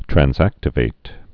(trăns-ăktə-vāt, trănz-)